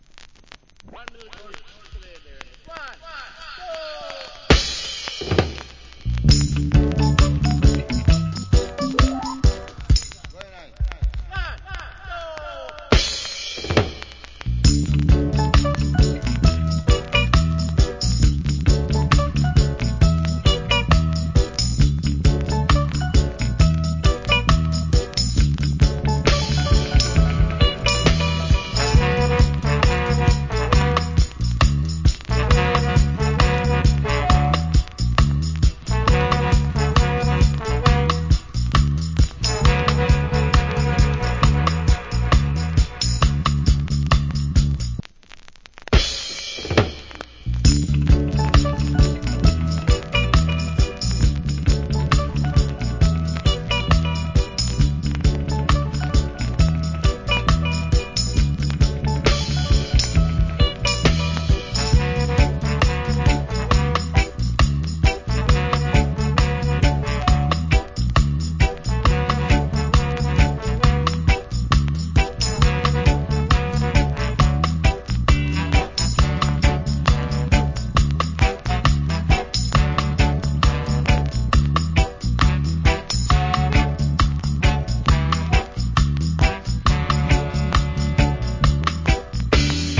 Nice Reggae Inst.